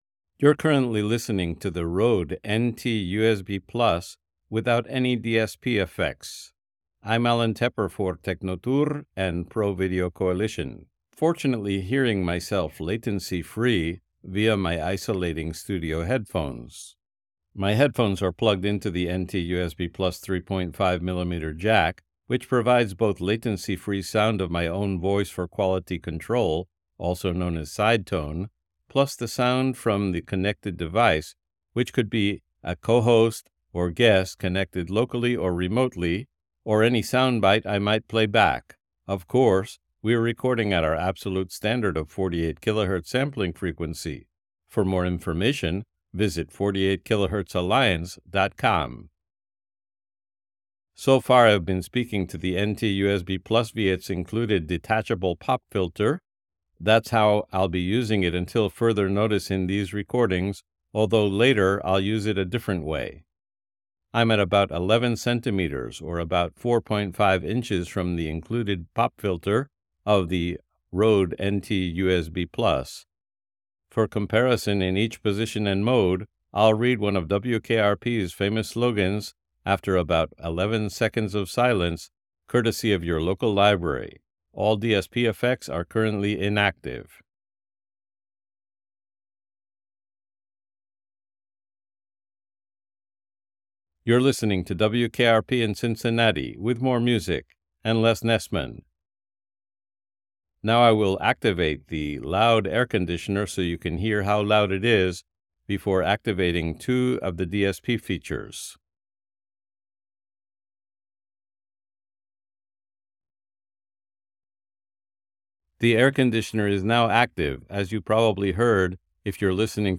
Test recordings, after one-click processing with Descript Studio Sound
This recording is the same one you heard above, after the one-click «StudioSound» from Descript (reviewed here).
NT-USB-Plus-Studio-Sound.wav